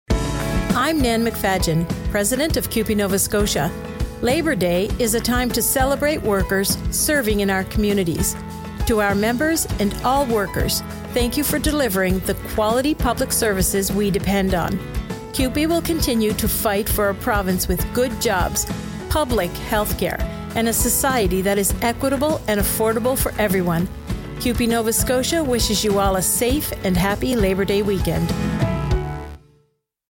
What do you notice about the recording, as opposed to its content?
Listen to our Labour Day radio message, playing on stations across the province until September 5.